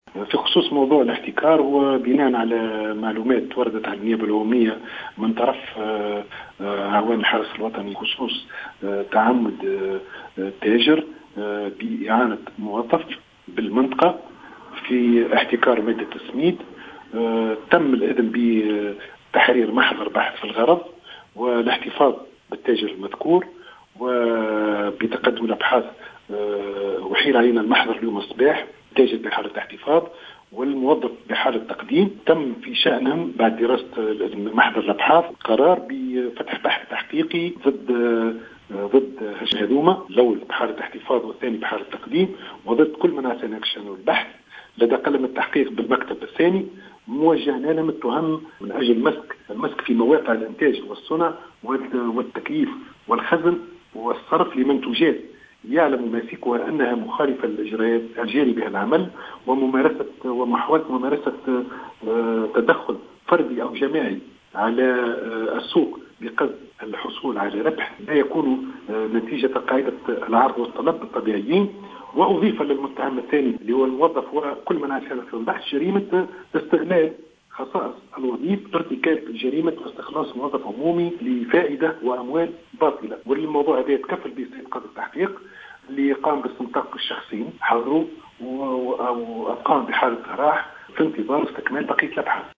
وكيل الجمهورية بسوسة هشام بسباس